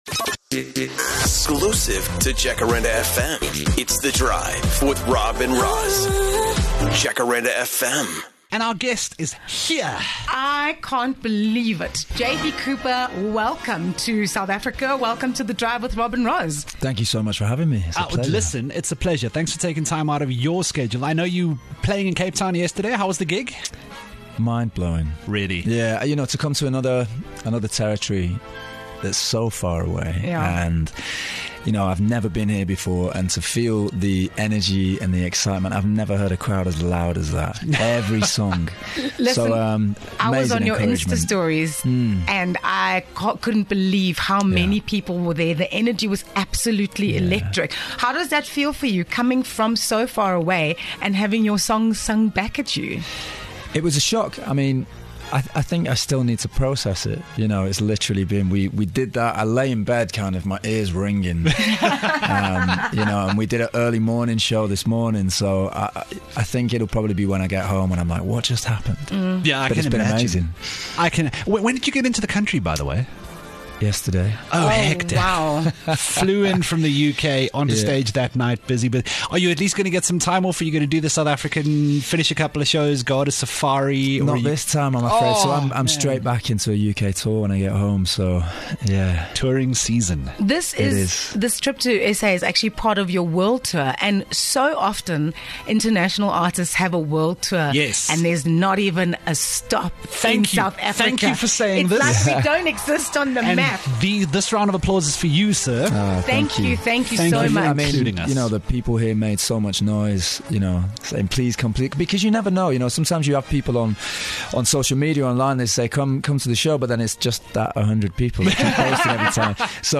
One of Great Britain's best musical exports has finally touched down in SA and has made his way to the Jacaranda FM studios!